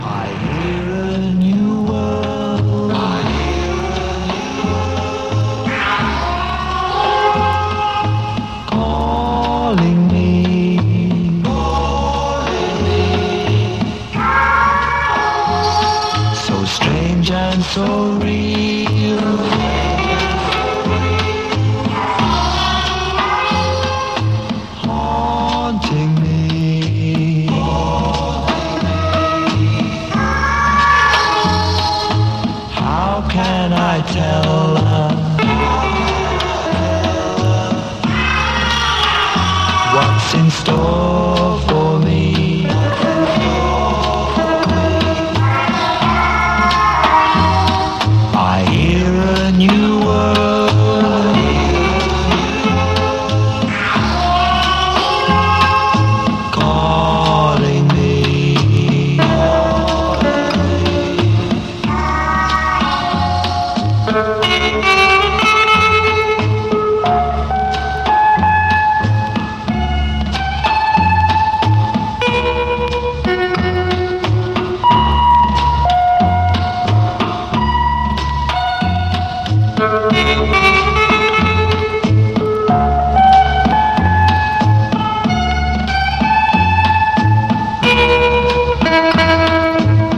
ストレンジでサイケデリックなアウトサイダー・ミュージック金字塔！